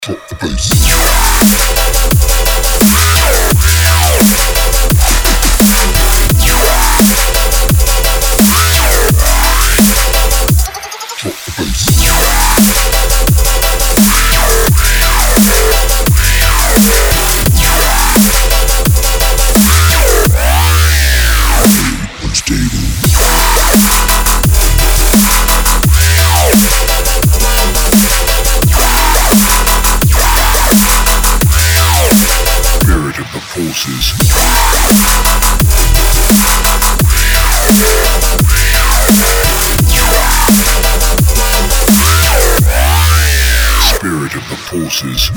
• Качество: 320, Stereo
Жесткий Дабчик!